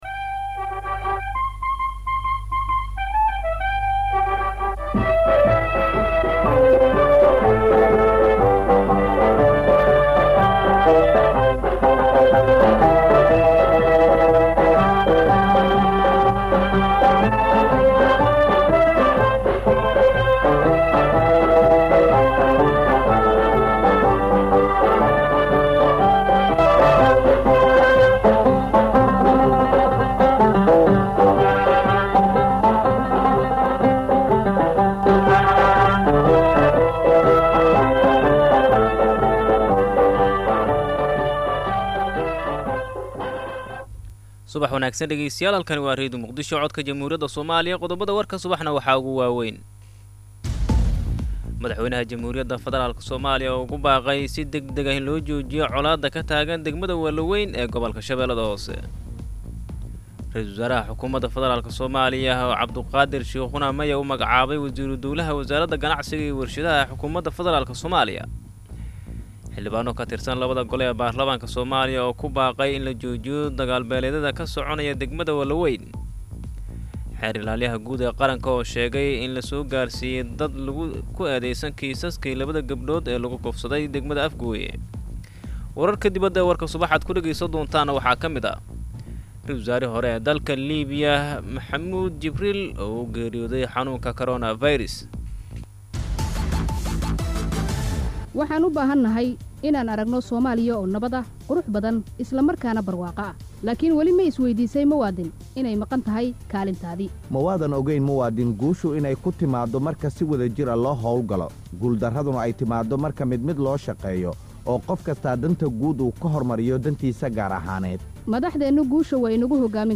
Dhageyso Warka Subax ee Radio Muqdisho.